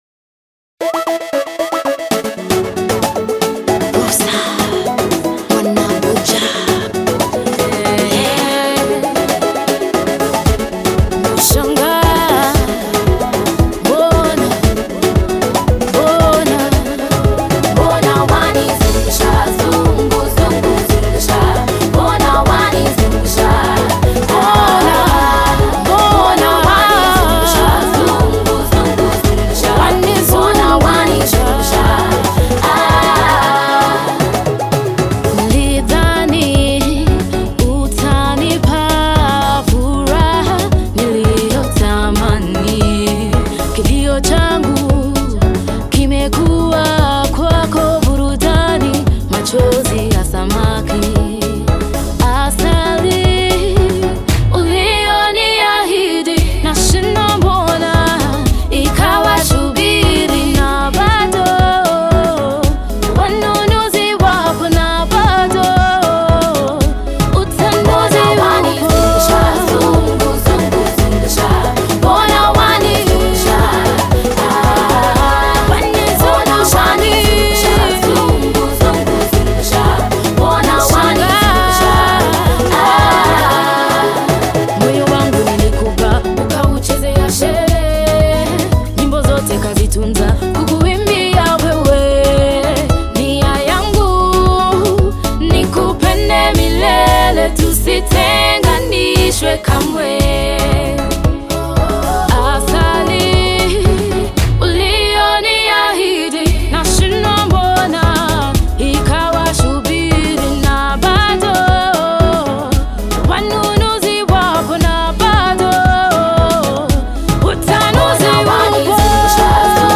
Pop Number
could have been Mixed better